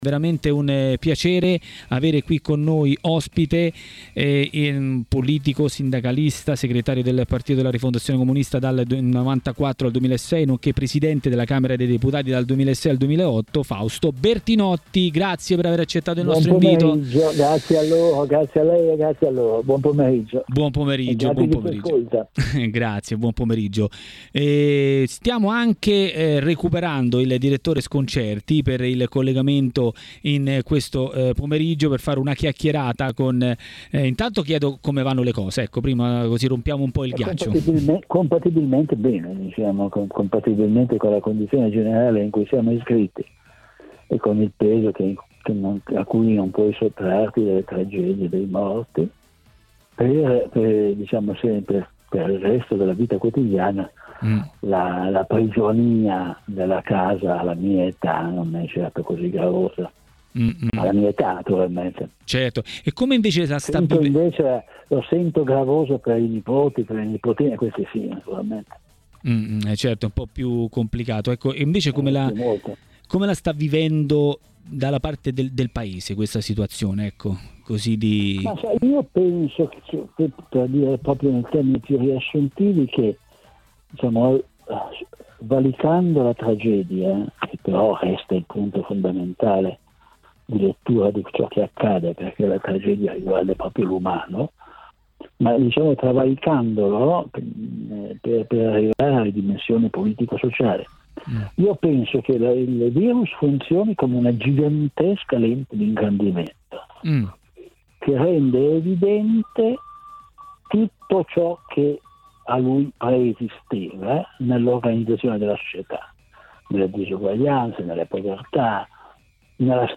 Scherza così a Maracanà, nel pomeriggio di TMW Radio, Fausto Bertinotti, ex presidente della Camera e leader di Rifondazione Comunista.